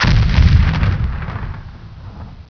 explode1.wav